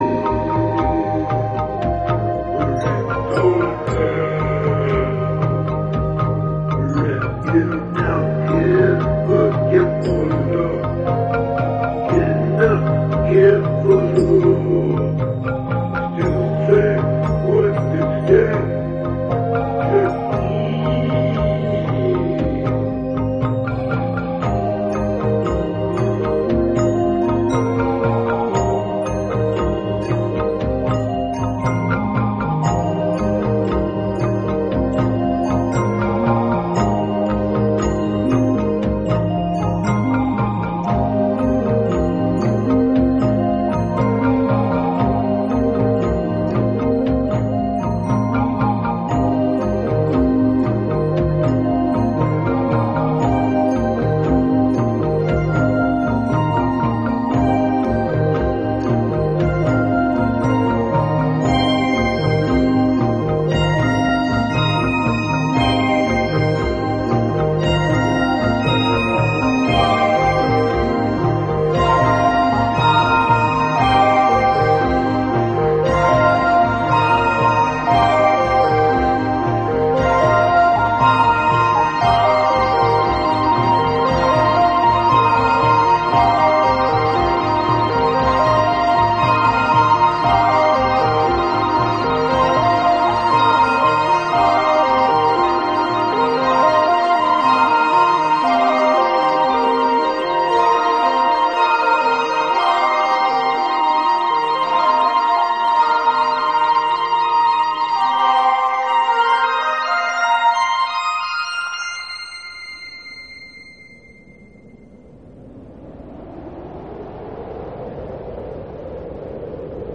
Press The Button is an interactive radio experience which allows the listeners, the staff, and guests to direct the flow of audio on any given show. At times free-form, at times orchestrated, Press the Button is a unique experiment in audio exploration.